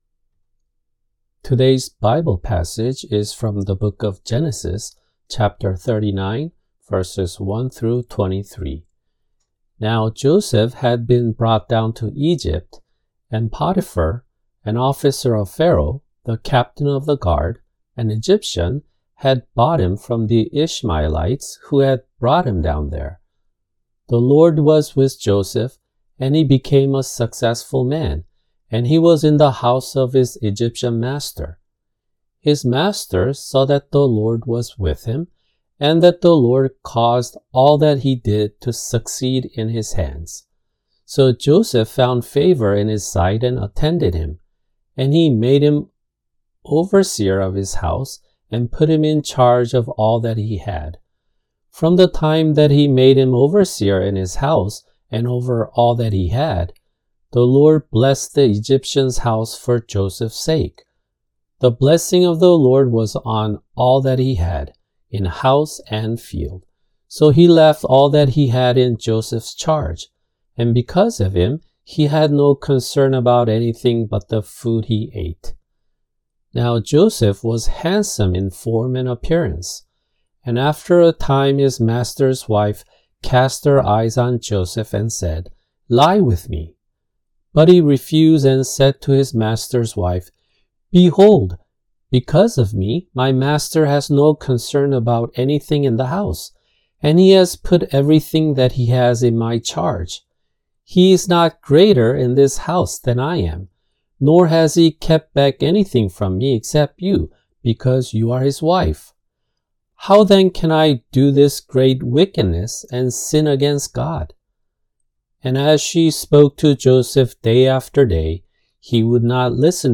[주일 설교] 창세기 39:1-23 (요셉-3)